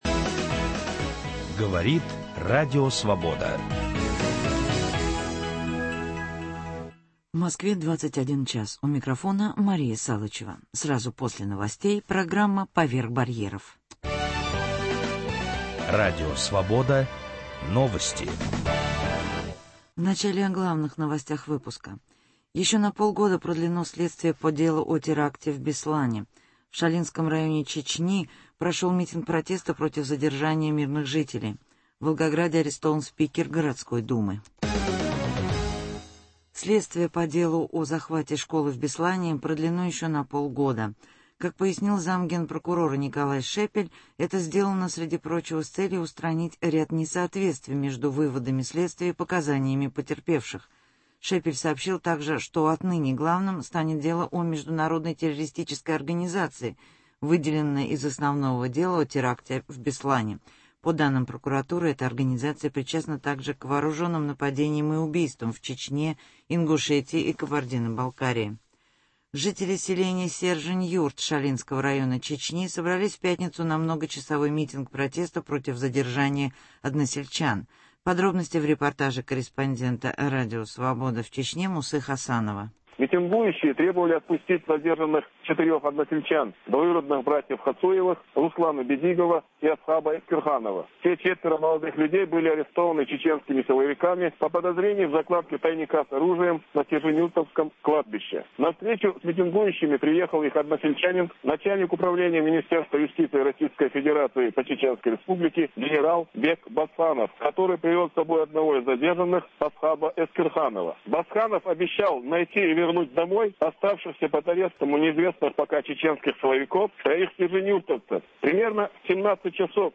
Немецкие переводчицы русской литературы вспоминают о драматических эпизодах в своей карьере. Интервью Иосифа Бродского, сентябрь 1991 год.